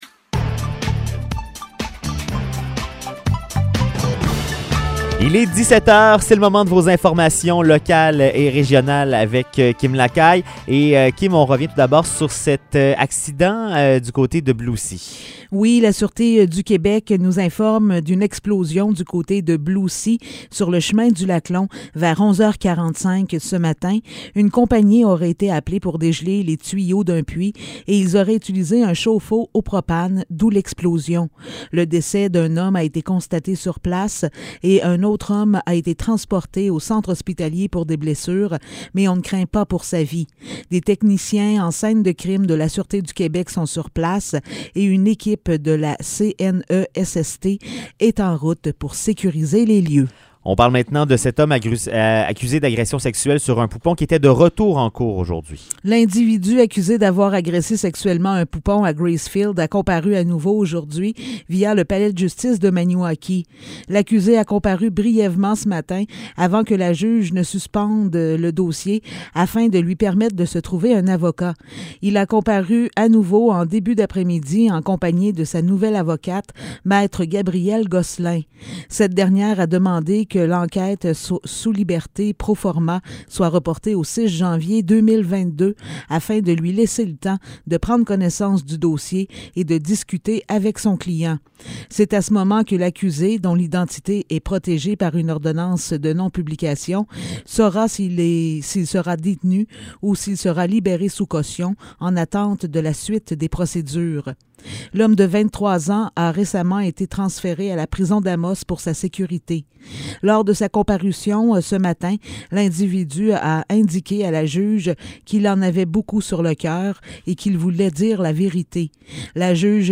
Nouvelles locales - 23 décembre 2021 - 17 h